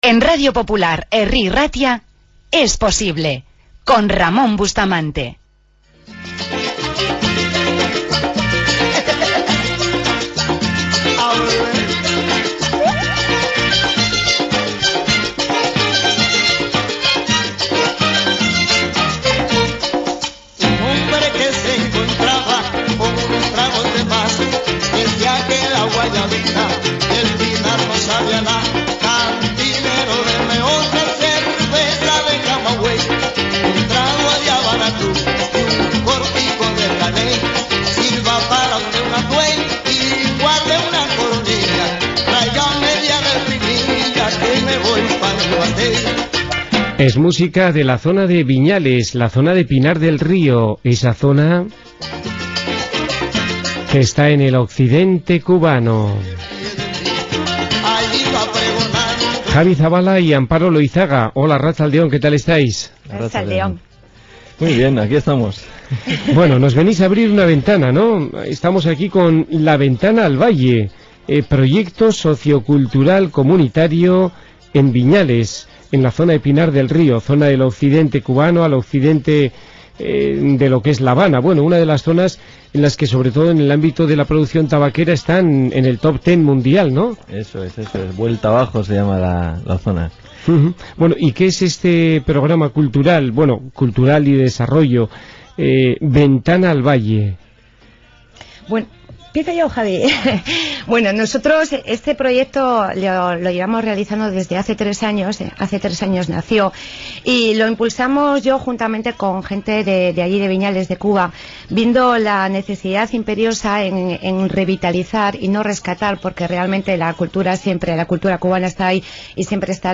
Entrevista en Radio Popular de Bilbao
entrev_radio_popular_ventanaalvalle.mp3